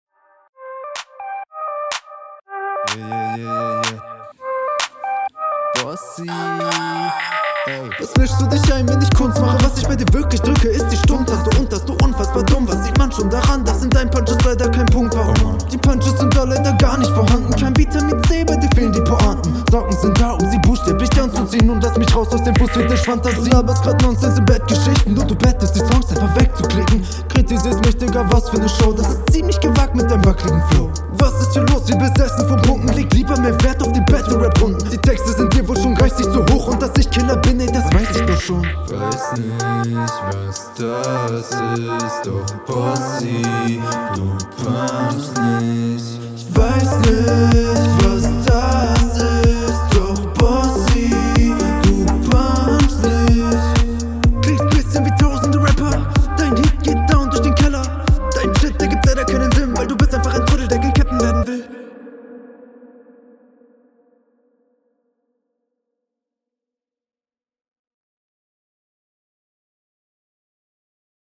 Flow cleaner als bei deinem Gegner.